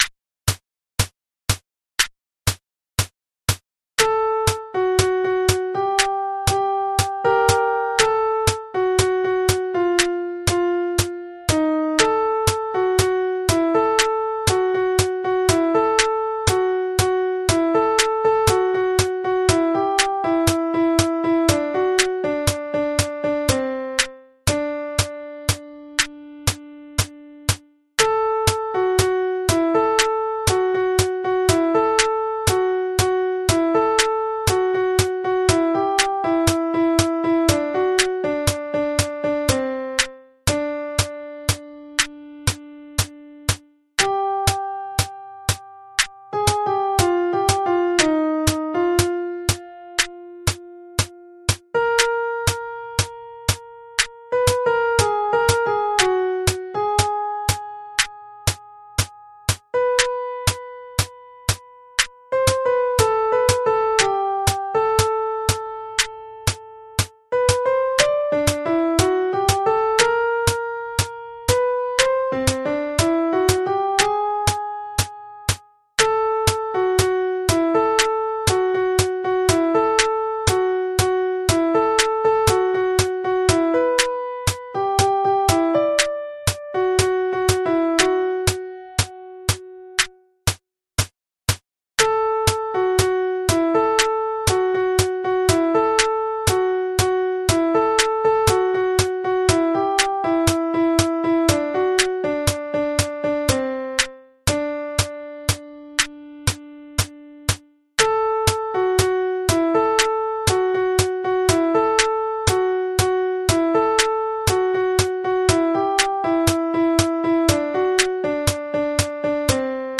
Sopran – Klavier + Metrum